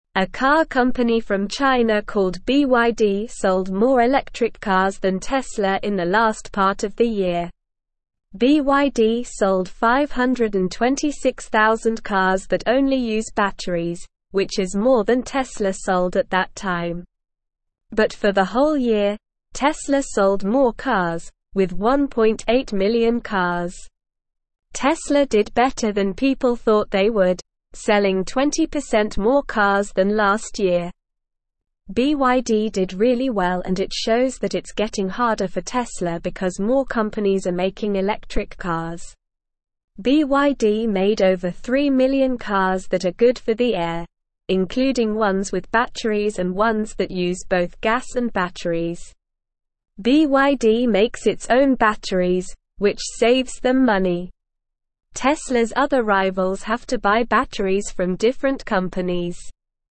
Slow
English-Newsroom-Beginner-SLOW-Reading-BYD-sells-more-electric-cars-than-Tesla.mp3